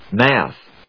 /mˈæθ(米国英語)/